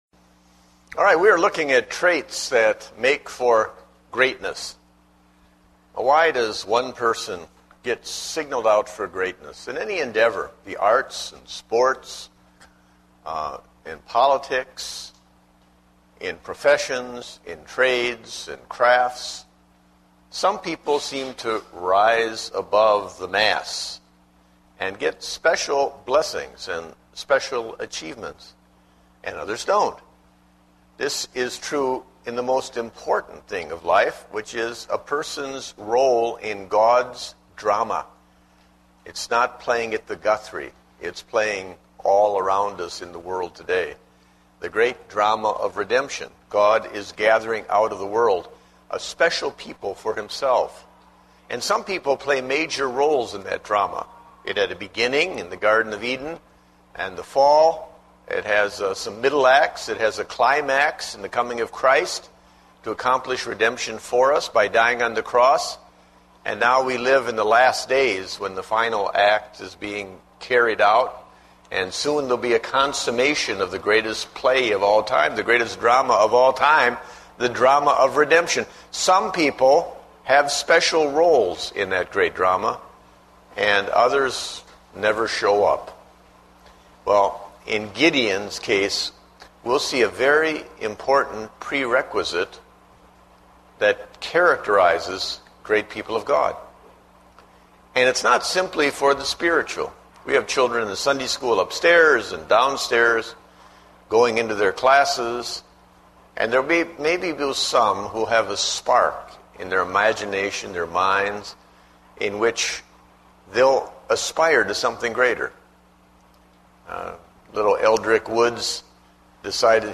Date: August 2, 2009 (Adult Sunday School)